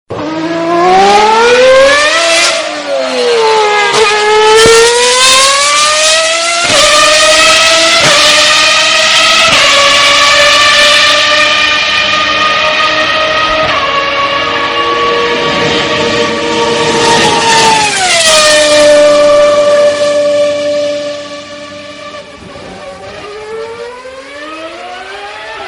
Car Driving In Speed Sound Effect Free Download
Car Driving In Speed